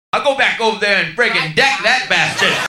Category: Comedians   Right: Personal